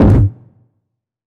GS Phat Kicks 025.wav